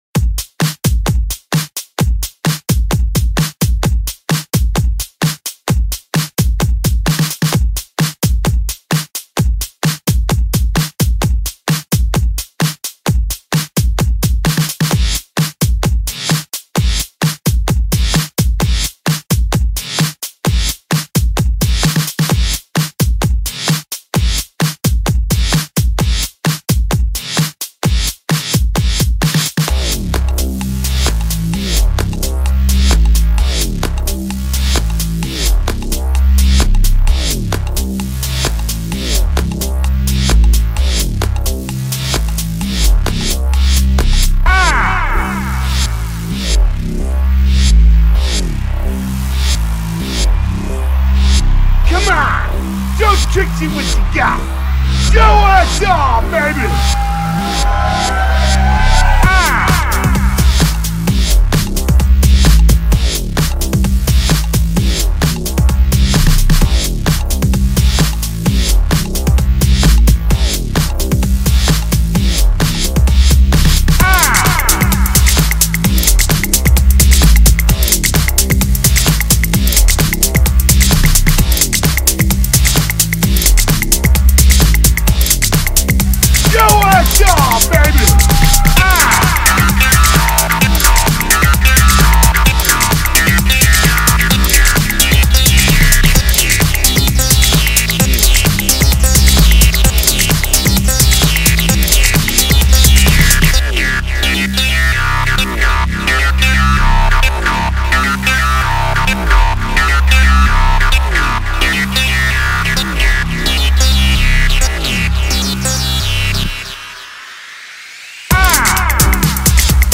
Nice Throw Back to my more Minimalistic Breakbeat Past.